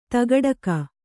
♪ tagaḍaka